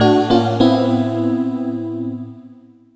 CHORD39 01-R.wav